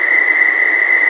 engine-turbine.wav